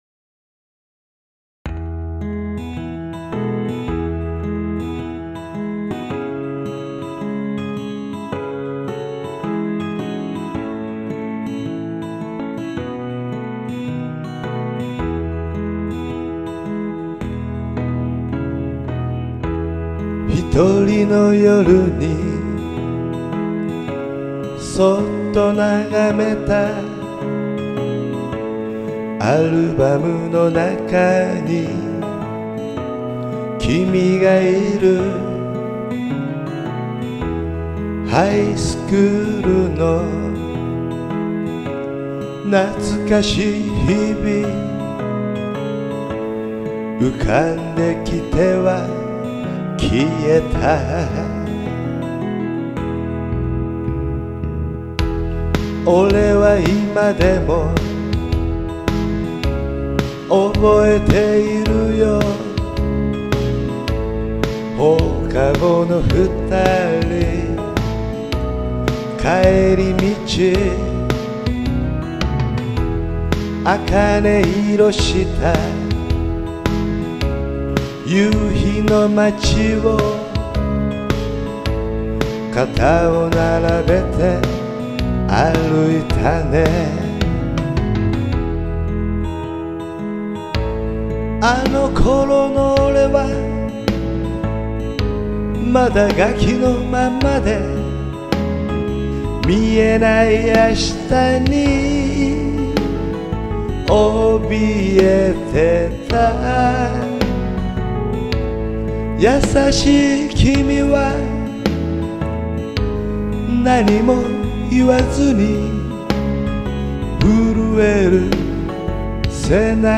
Ballad (medium tempo)